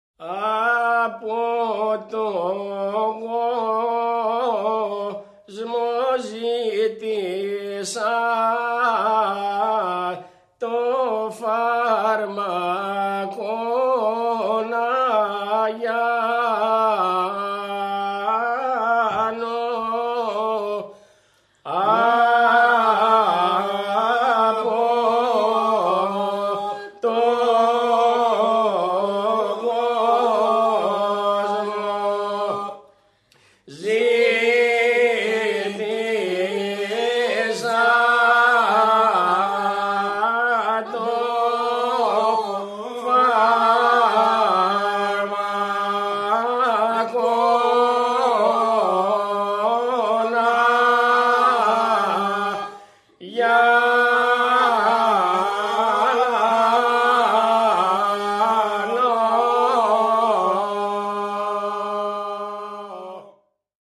Πλωμαρίτικος Πλωμάρι | 1994 αργός τοπικός σκοπός Τραγούδι_ μικτός όμιλος